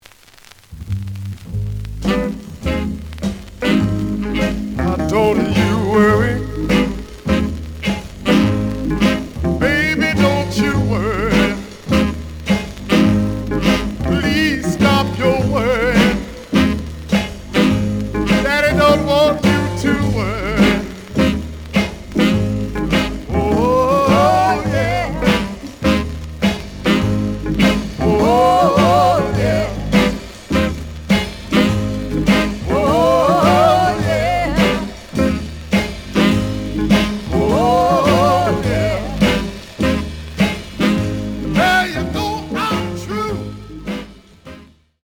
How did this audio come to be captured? The audio sample is recorded from the actual item. Some noise on A side.)